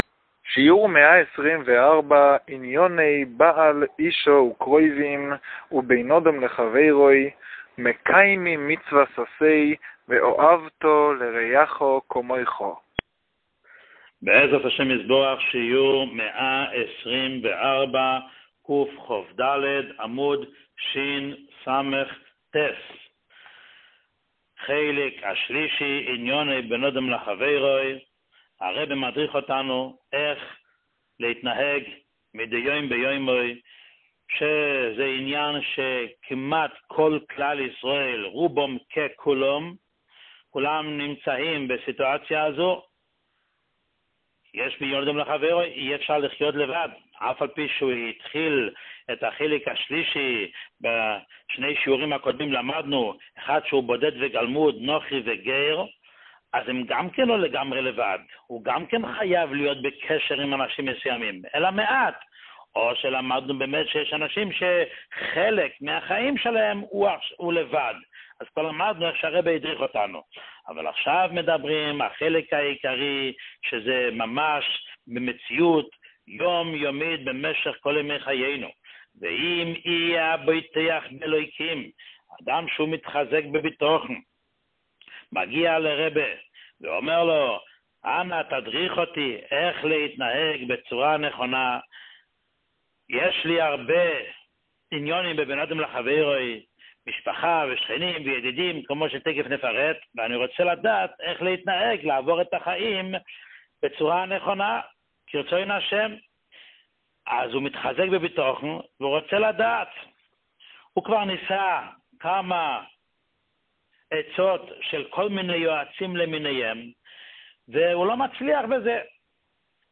שיעור 124